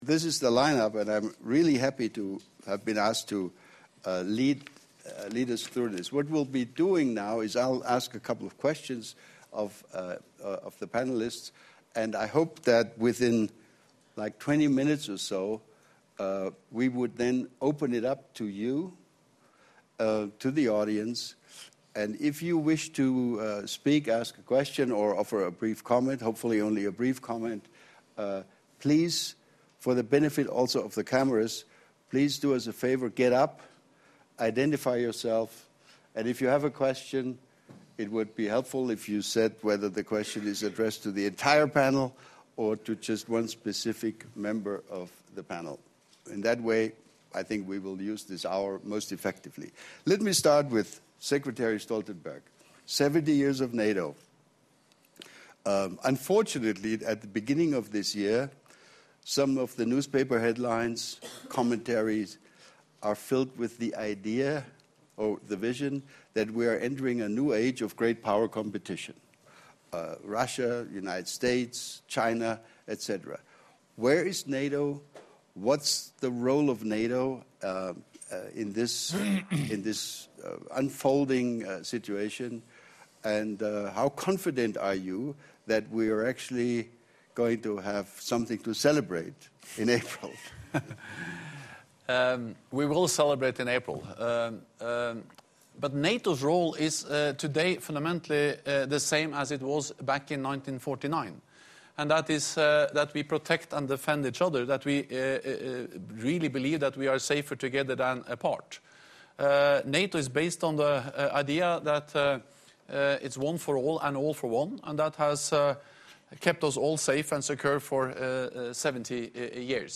Session at the World Economic Forum with participation of NATO Secretary General Jens Stoltenberg